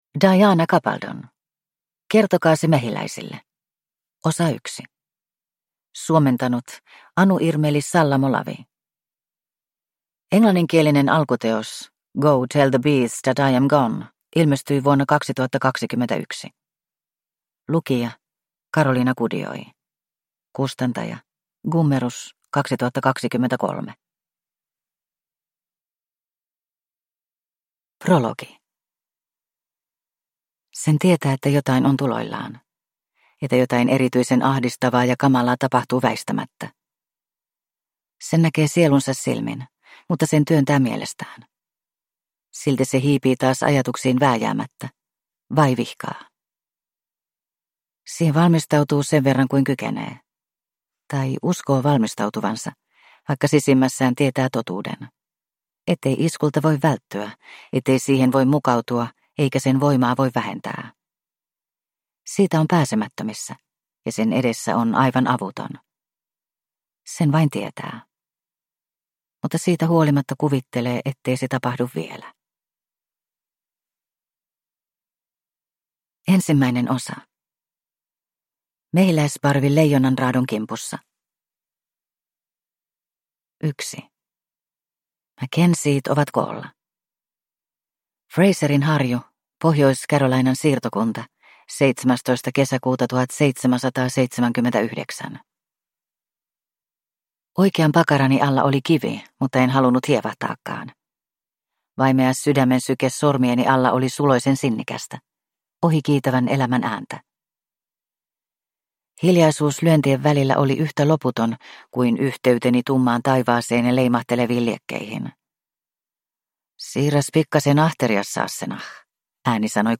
Kertokaa se mehiläisille, osa 1 (ljudbok) av Diana Gabaldon